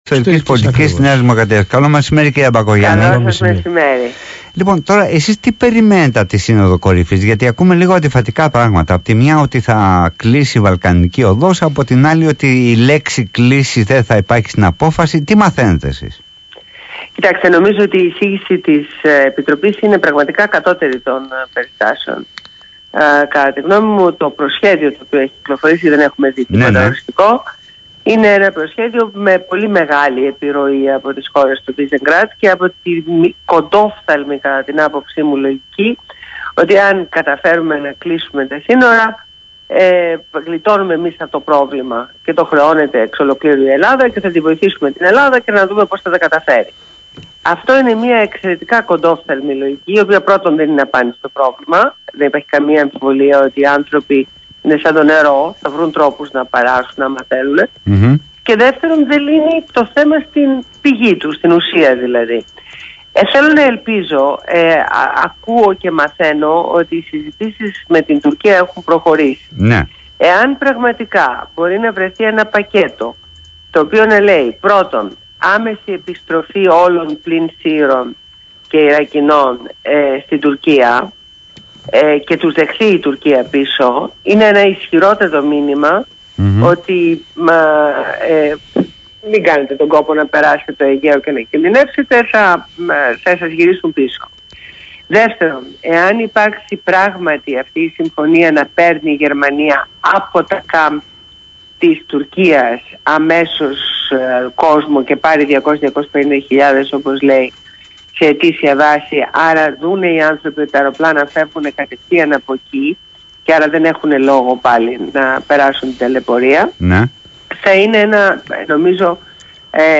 Συνέντευξη στο ραδιόφωνο BHMAfm